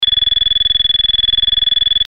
ZUMBADOR CON 8 SONIDOS
Zumbador Electrónico Empotrable para cuadro Ø 22,5MM
dB 86-100